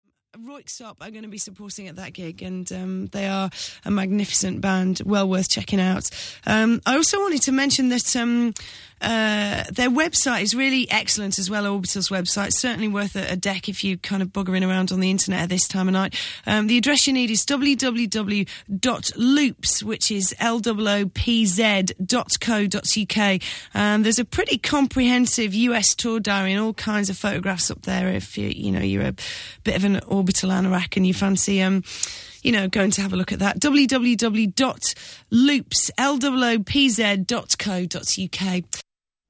6th November 2001 -Mary Anne Hobbs - Breezeblock Sessions - Radio Broadcast